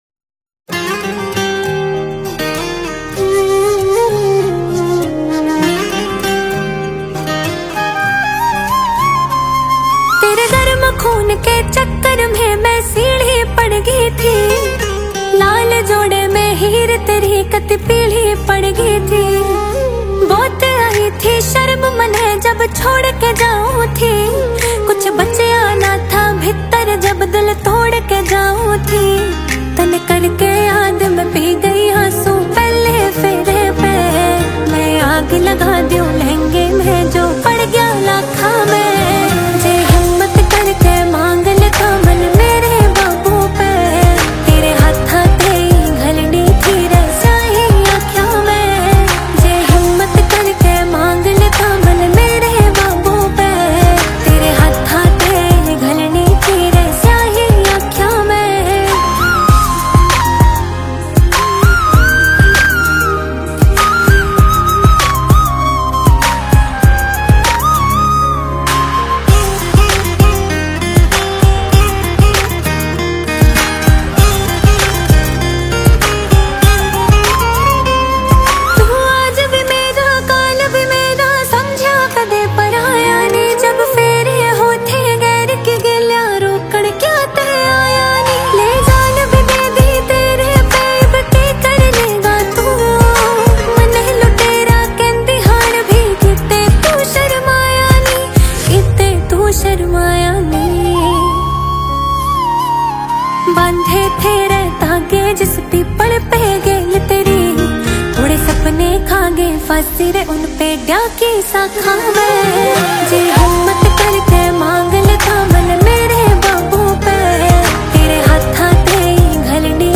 emotional Haryanvi song